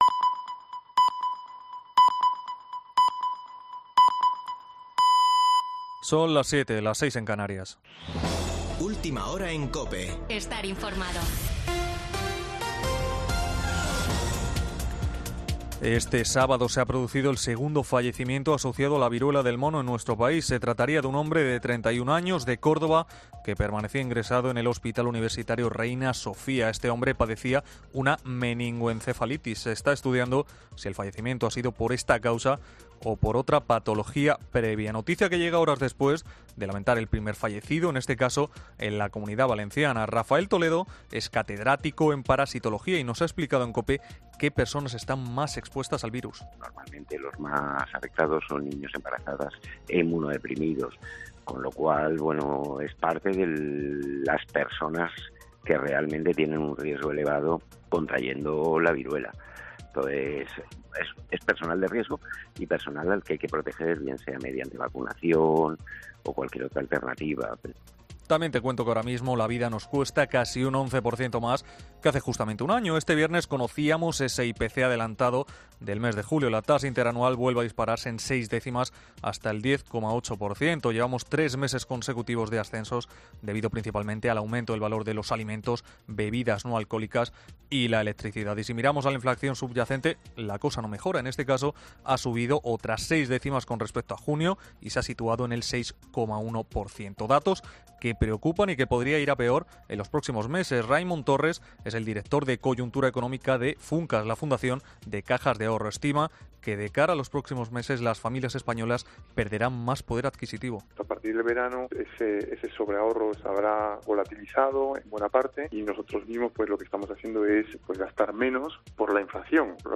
AUDIO: Boletín de noticias de COPE del 30 de julio de 2022 a las 19.00 horas